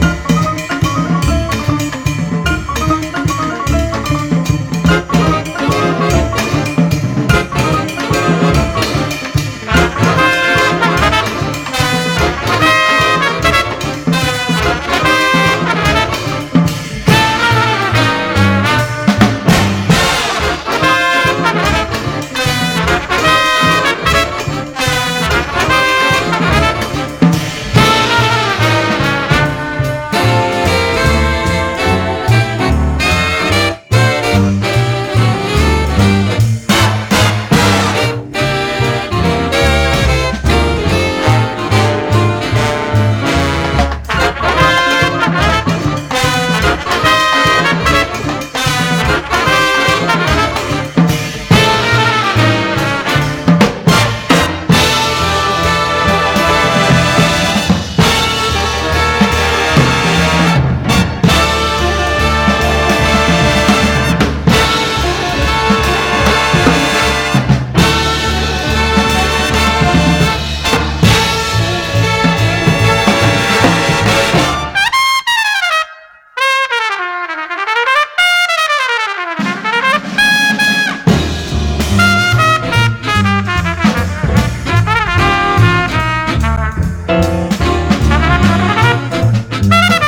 オリエンタルな和楽器ブレイクやエレピ・ジャズ・ファンクを収録した非売品レコード！